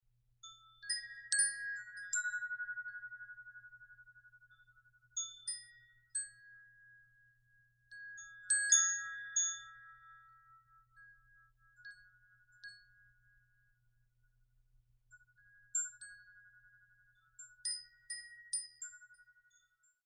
Tuned to the first notes of the whole tone scale used in Claude DeBussy's Le Mer (The Sea), this windchime has a sensuous tone that offers wonderful harmonies as it is played randomly by the wind.
Teak finish Ash wood, 4 bronze anodized aluminum tubes, rust finish steel windcatcher, genuine stone accents.